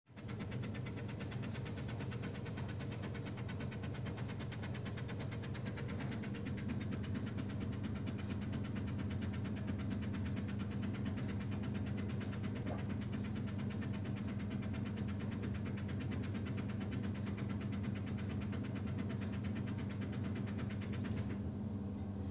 Bruit de locomotive chaudière Mégalia Elm Leblanc
Ce bruit est issu de micro coupure en alimentation gaz du brûleur.
Il n'apparait donc que lorsque le brûleur fonctionne et uniquement en chauffage.
2-mars-a-09.13-chaudiere.mp3